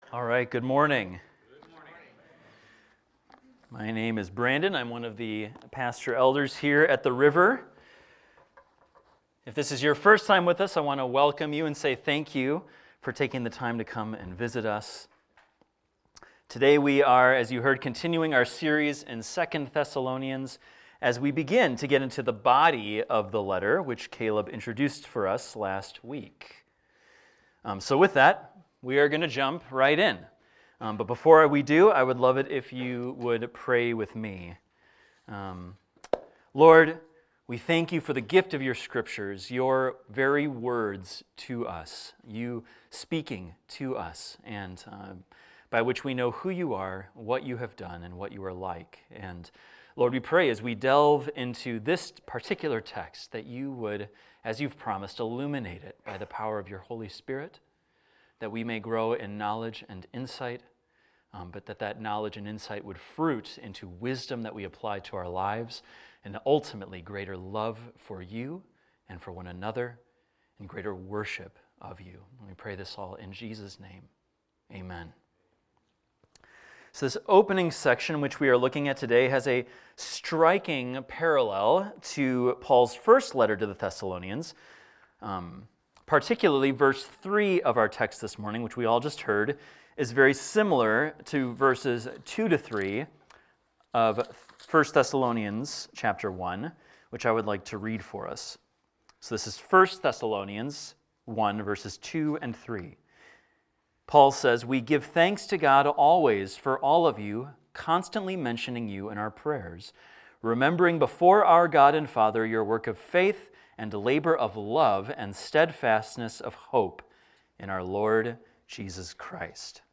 This is a recording of a sermon titled, "Abundant Growth."